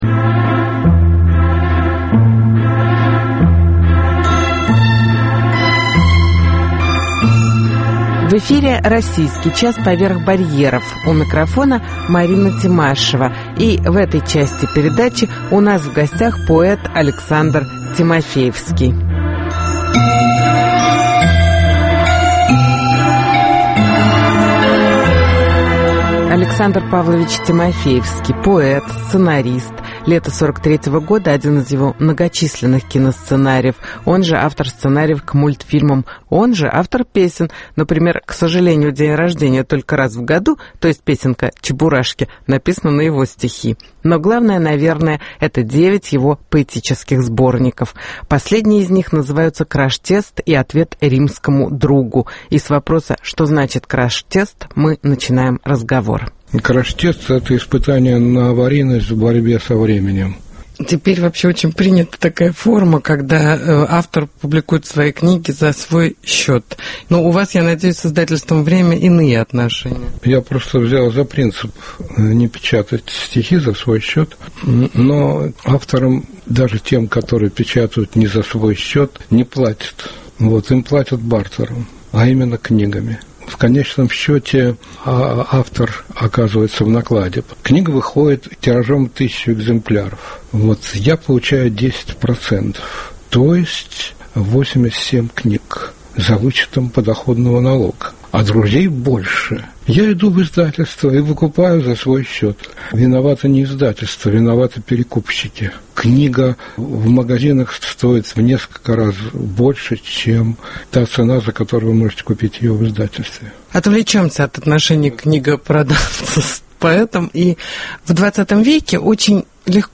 Интервью с Александром Тимофеевским